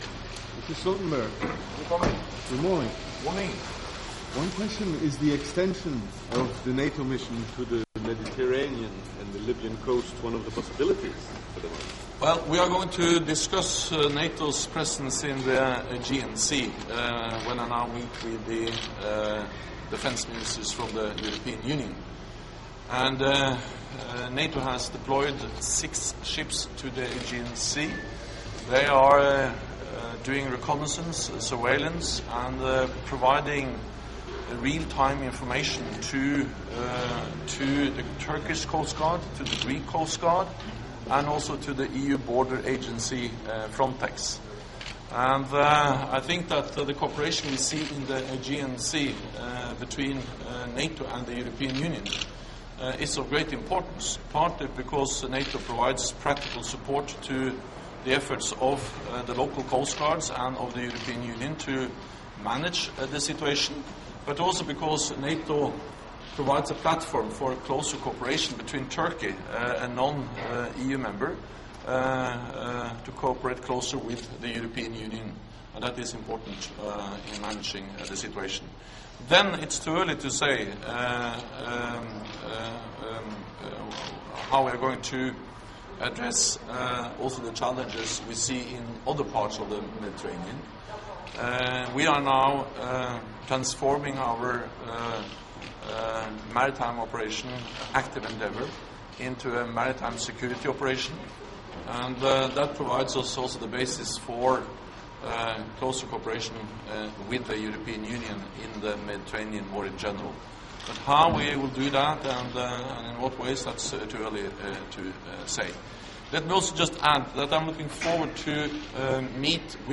Speaking ahead of the EU Defence Ministers meeting in Luxembourg on Tuesday (19 April) NATO Secretary General Jens Stoltenberg welcomed close cooperation between NATO and the EU to respond to the refugee and migrant crisis.